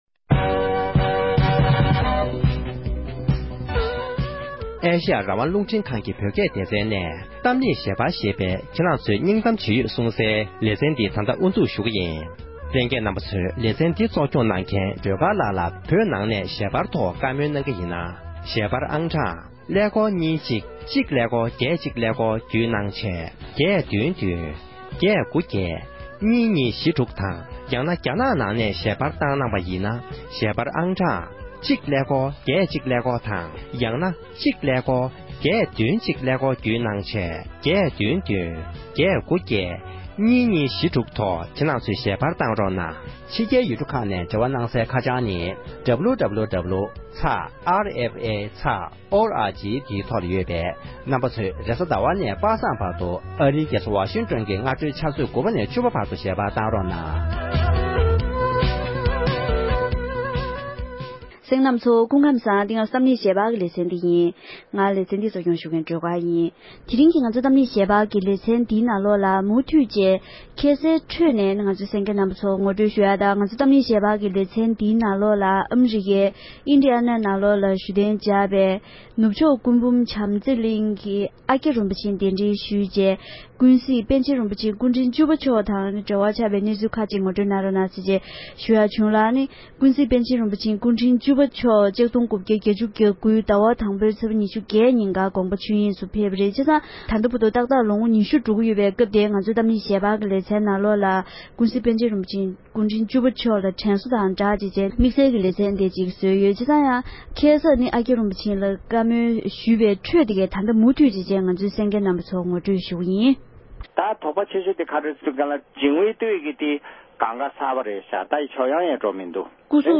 པཎ་ཆེན་རིན་པོ་ཆེ་སྐུ་གོང་མའི་རྗེས་དྲན་གྱི་བགྲོ་གླེང༌།